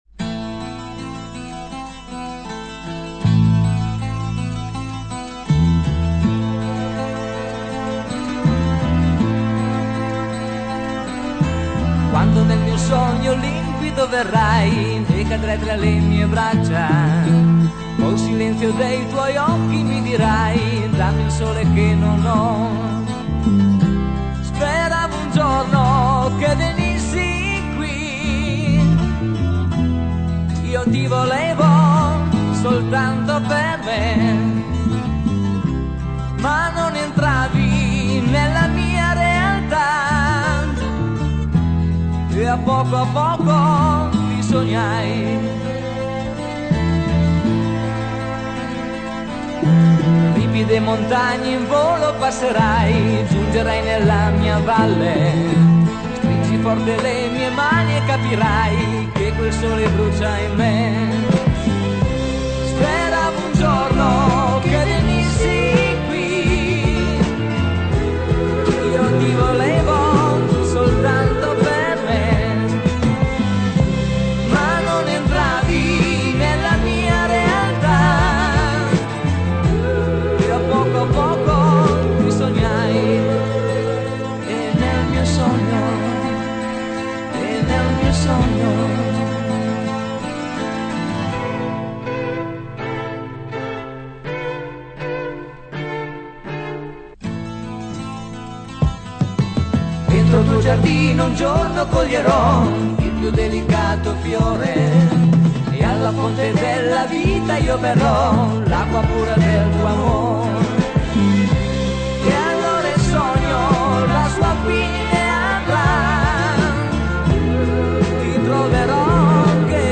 (Provini realizzati in sala prove)